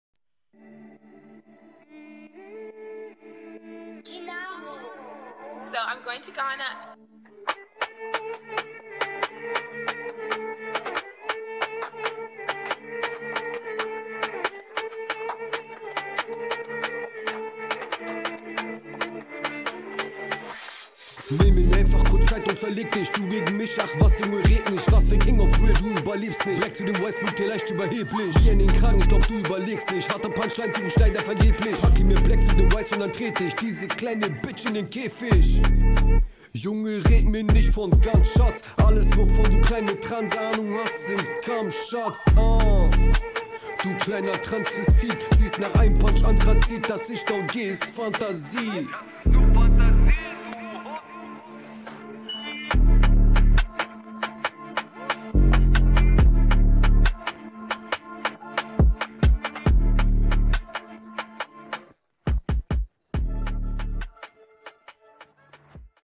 Flow gut und routinierter als beim Gegner, aber bisschen wenig Gegnerbezug bei den Lines und …
bruh 40 sekunden rap in 1:20 minuten hättest du bisschen cutten können